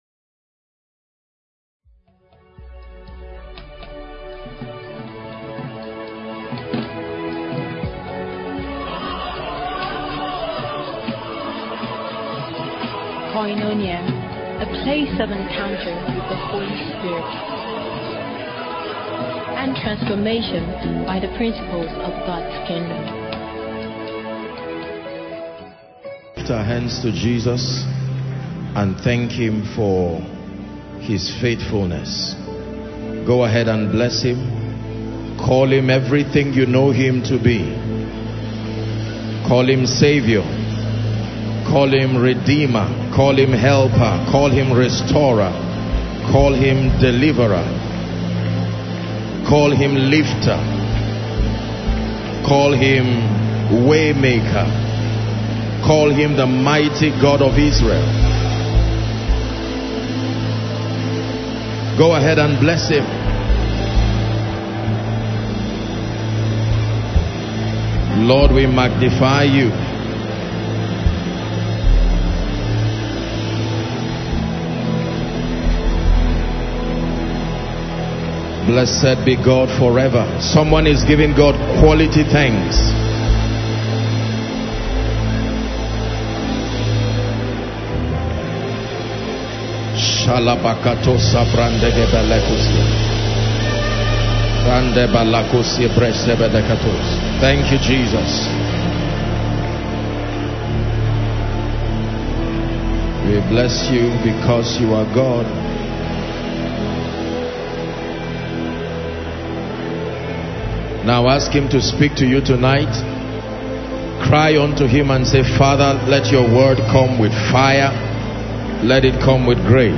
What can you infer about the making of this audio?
VENUE KOINONIA, Abuja